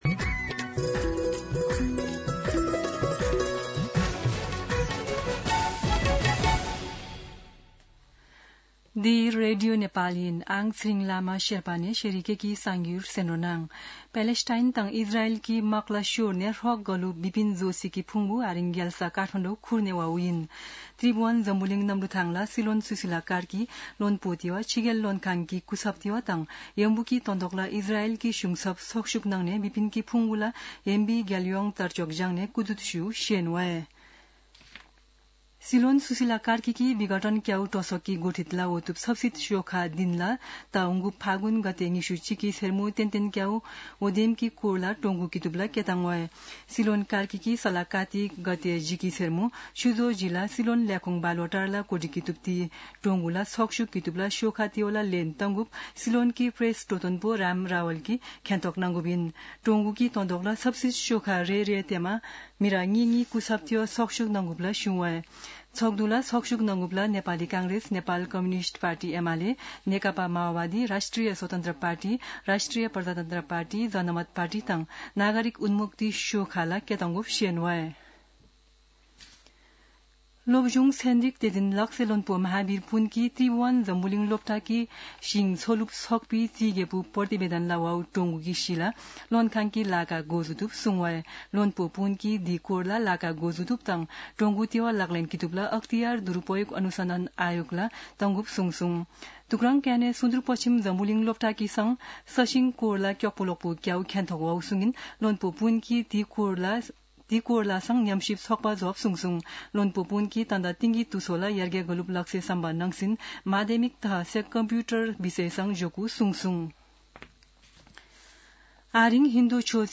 शेर्पा भाषाको समाचार : ३ कार्तिक , २०८२